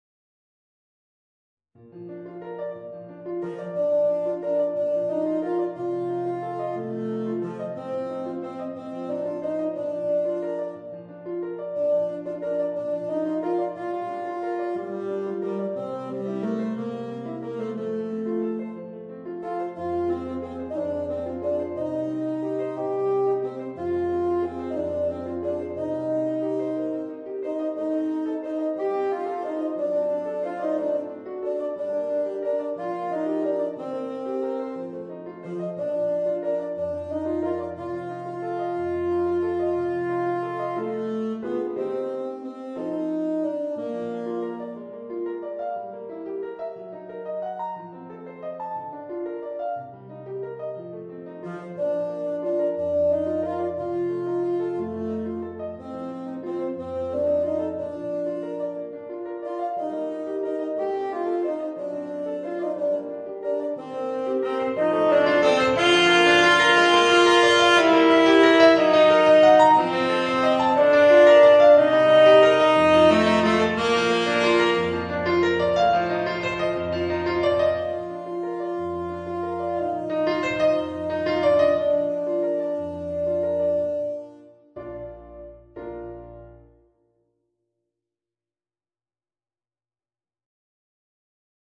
Saxophone ténor & piano